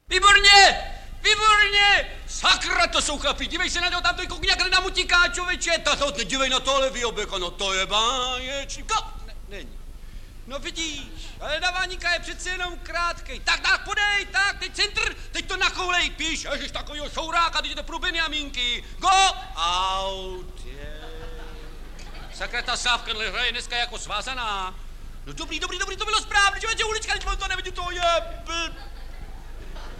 Audiobook
Audiobooks » Humour, Satire & Comedy
Read: Barbora Hrzánová